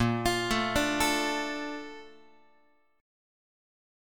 BbM7 chord